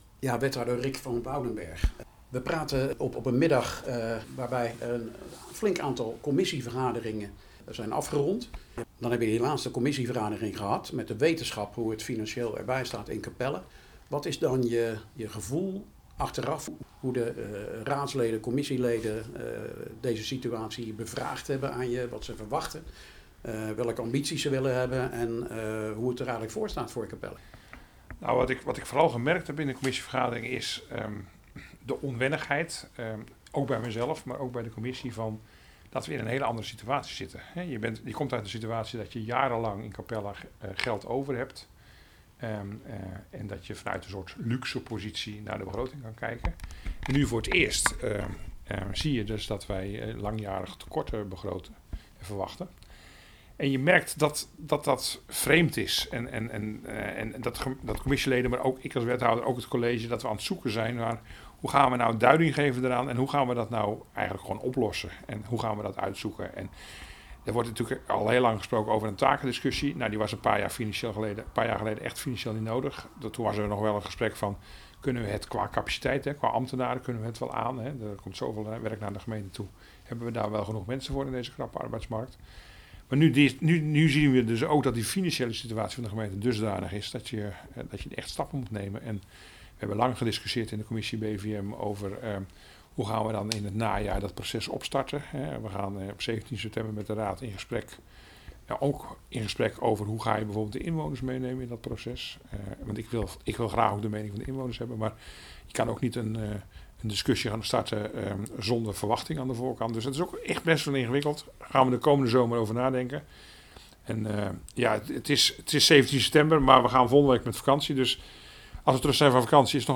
ging in gesprek met Rik van Woudenberg in aanloop naar de raadsvergadering